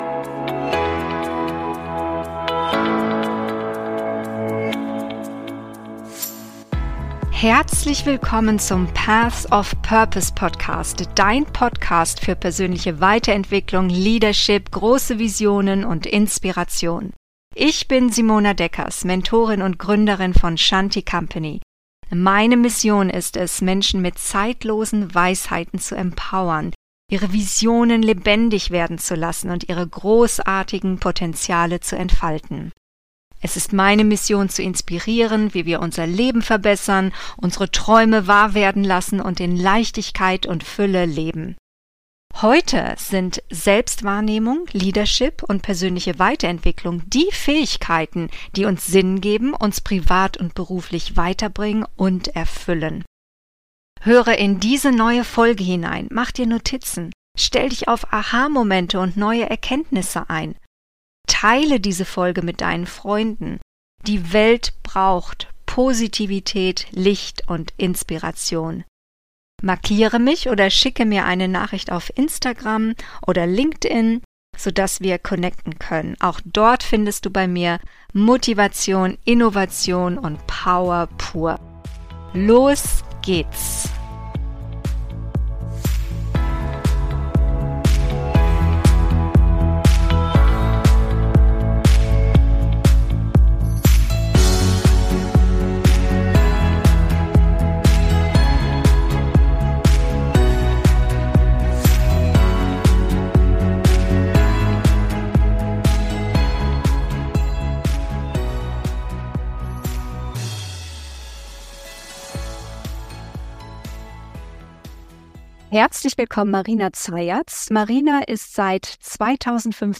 Personal Branding für CEOs: So geht's! - Interview